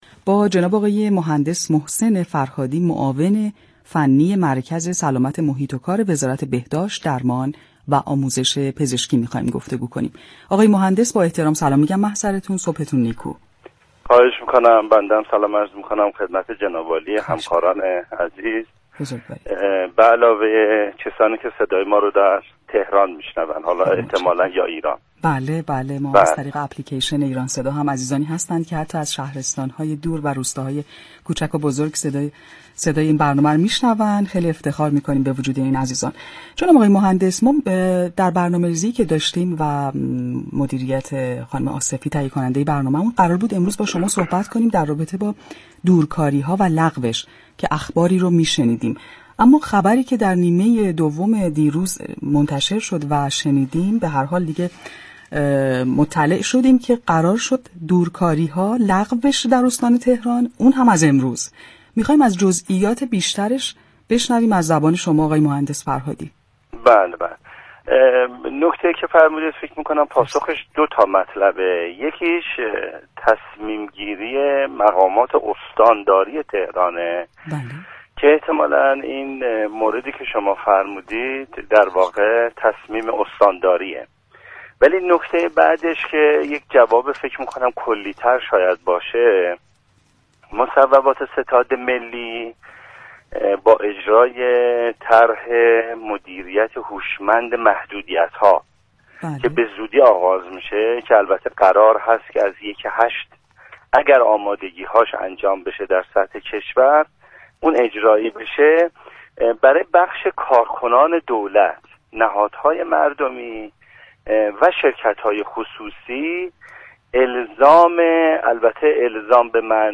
به گزارش پایگاه اطلاع رسانی رادیو تهران، محسن فرهادی معاون فنی مركز سلامت محیط و كار وزارت بهداشت در گفتگو با برنامه تهران ما سلامت رادیو تهران درباره مصوبه روز دوشنبه 26 مهرماه استانداری تهران مبنی بر لغو دوركاری كارمندان ادارات از روز سه شنبه 27 مهرماه گفت: لغو دوركاری كارمندان ادارات استان تهران از روز 27 مهر توسط مقامات استانداری اتخاذ شده است.